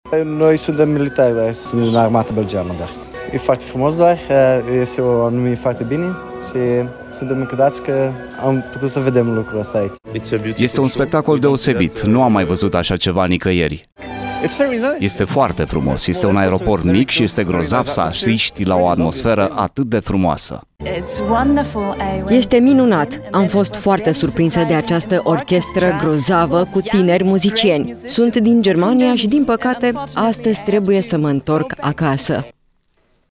La spectacolul din terminalul Aeroportului Brașov au asistat călători români și străini, printre care câțiva militari belgieni.
Voxuri-straini.mp3